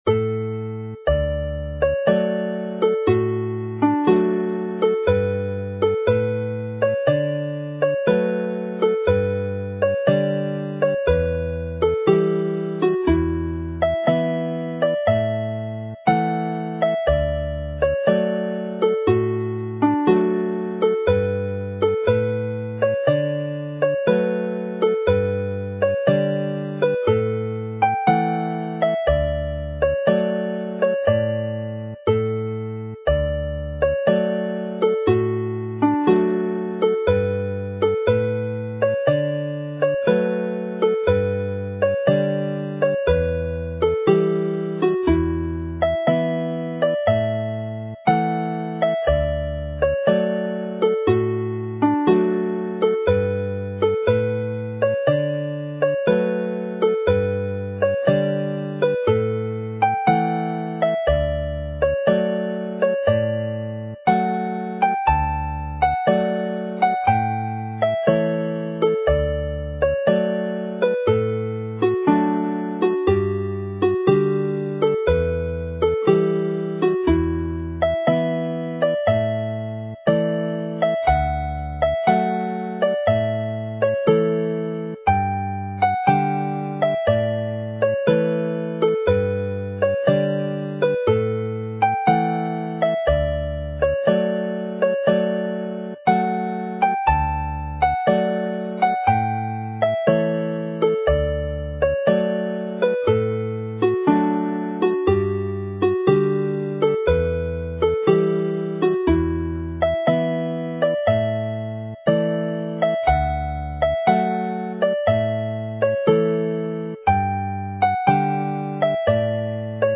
Hornpipes are played with a skip - dotted - but written in plain timing, like a reel, with the understanding that they can be interpreted either as a reel or a hornpipe.
Slow sound versions of the files are also included to make the tunes easier to learn by ear.